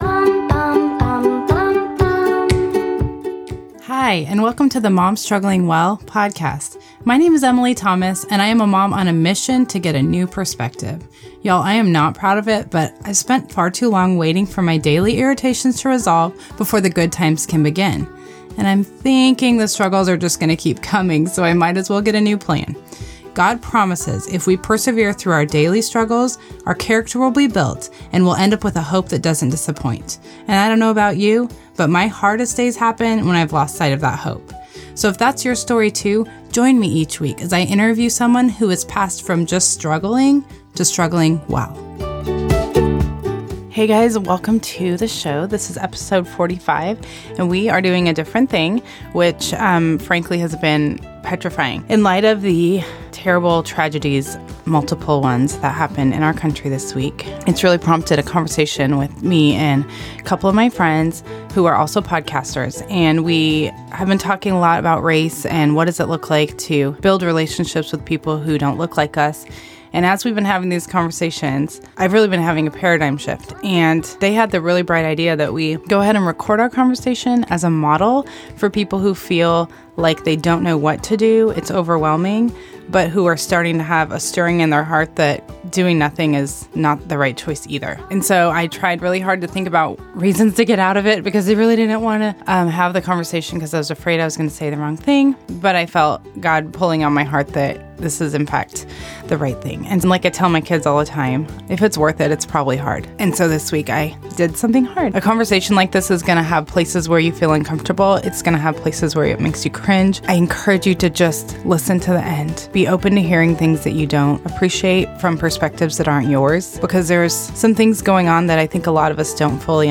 My friends and I are all podcasters and each come to the topic of race with different perspectives.